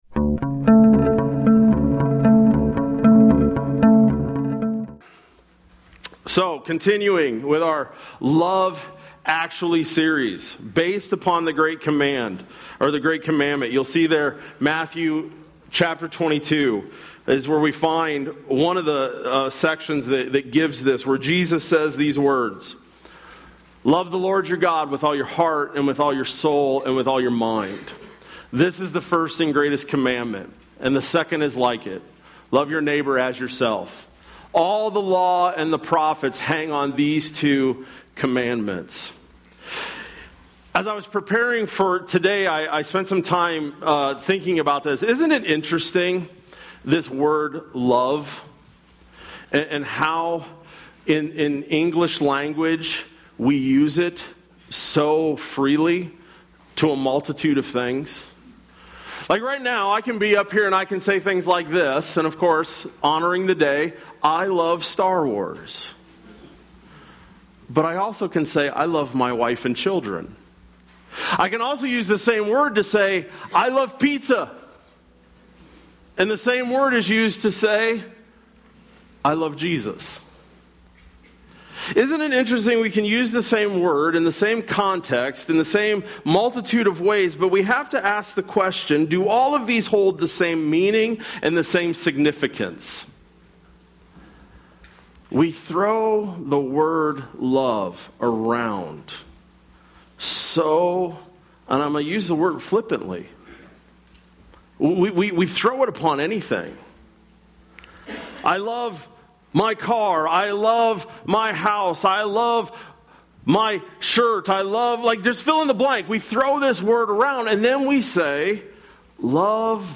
May-5-25-Sermon-Audio.mp3